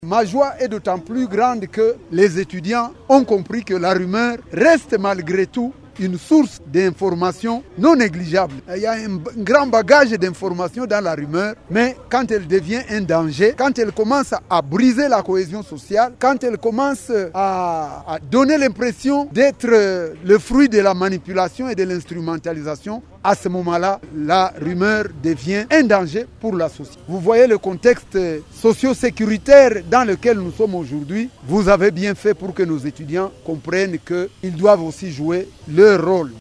Deux thèmes à savoir la gestion des rumeurs et la justice populaire face au Droit positif congolais ont été abordés au cours d’une conférence débat organisée samedi 21 juin 2025 par Radio Maendeleo en faveur des étudiants de l’Institut Supérieur Pédagogique ISP Bukavu.